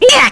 Lakrak-Vox_Damage_kr_01.wav